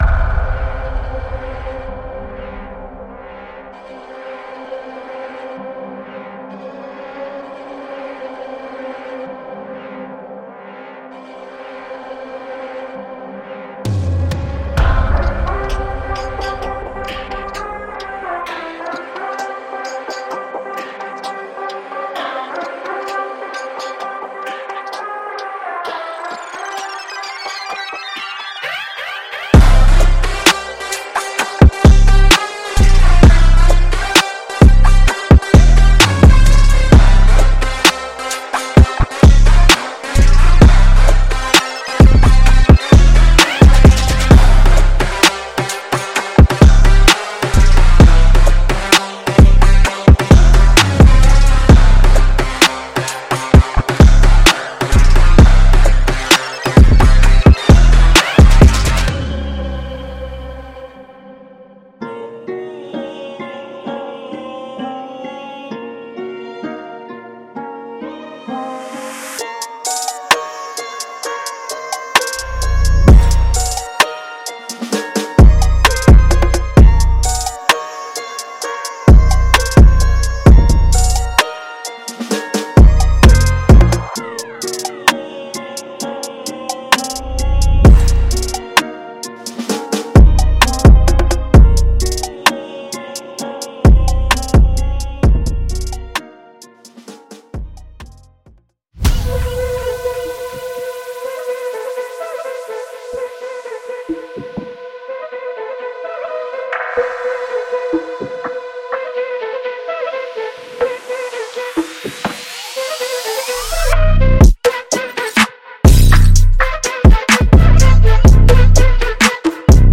我们制造过的最坚固的击鼓！
每个样本的设计都具有完美的谐波含量和刺穿的瞬变，有助于切入任何混音，同时听起来仍然充满特色。
这包括独特的鼓，有节奏的percs，现场振动器等所有东西，以提供更多的质感以激发灵感。
借助来自世界各地的广泛的折衷打击乐样本套件，您将拥有使自己的曲目与众不同的一切所需！